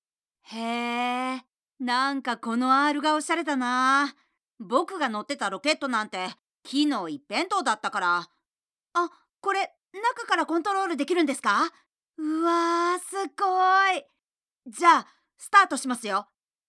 【声優ボイスサンプル】
ボイスサンプル5（少年）[↓DOWNLOAD]
声質は少しハスキーなところがあります。